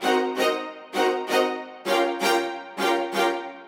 Index of /musicradar/gangster-sting-samples/130bpm Loops
GS_Viols_130-CG.wav